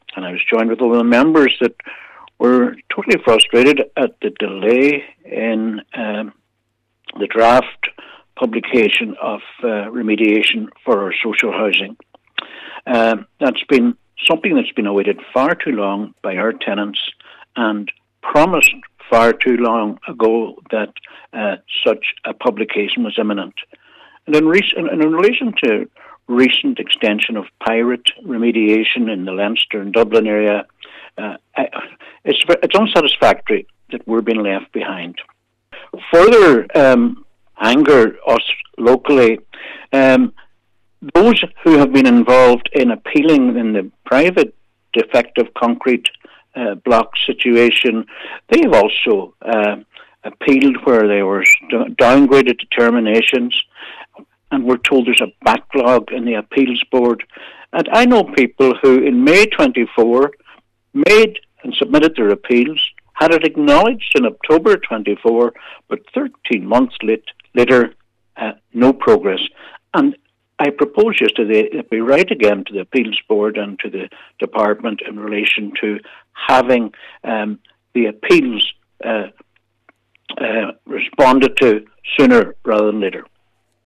Cllr Albert Doherty has said it is unsatisfactory people in the area are being left behind: